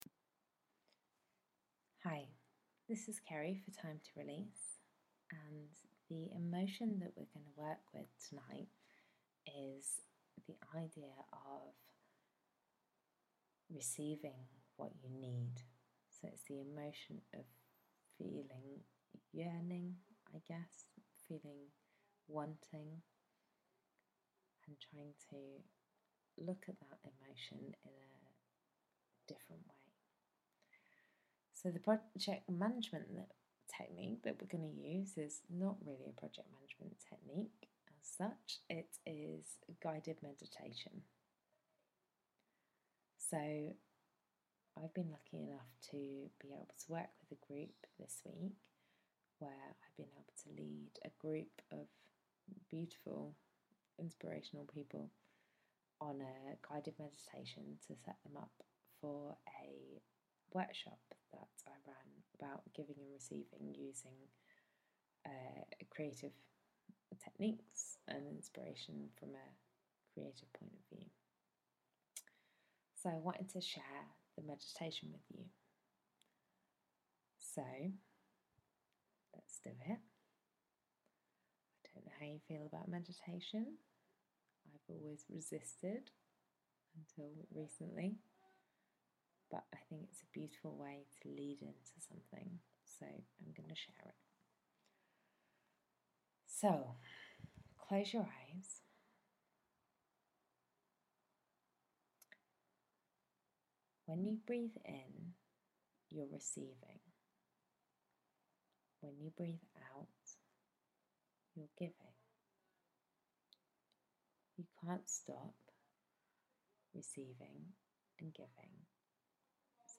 Project management technique: A guided meditation